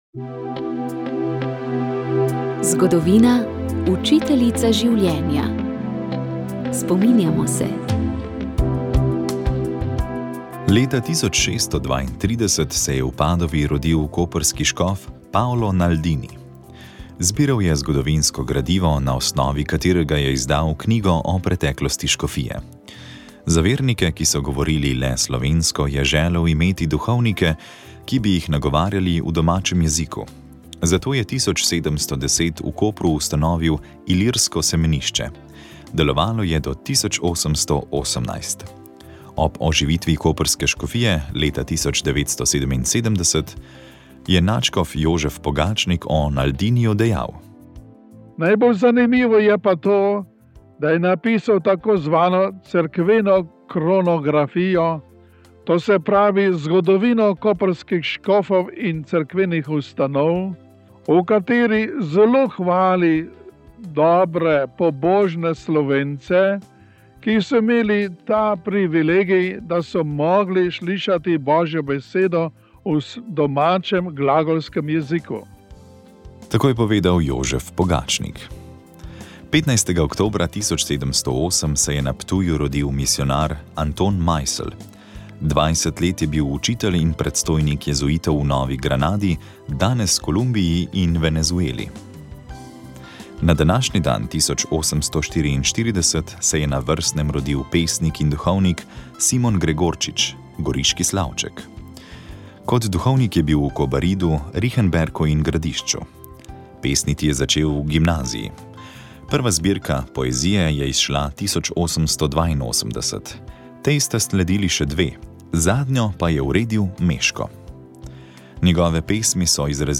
Molili so bogoslovci.